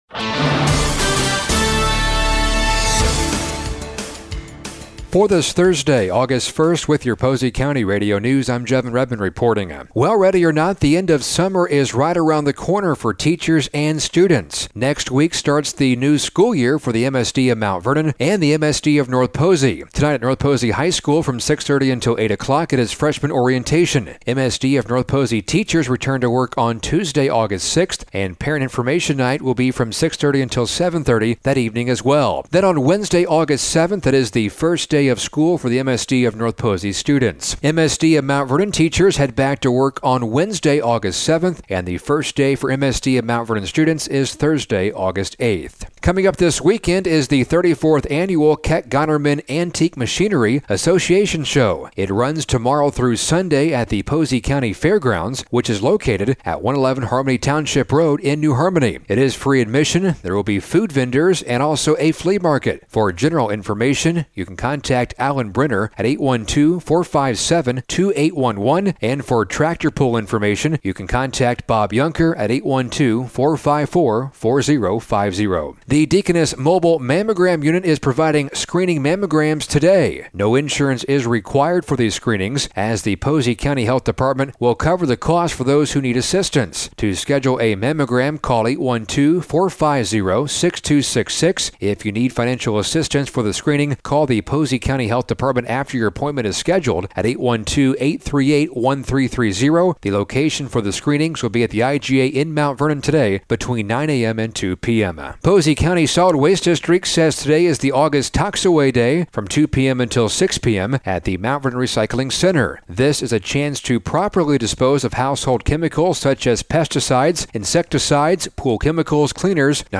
Posey County Radio News and Sports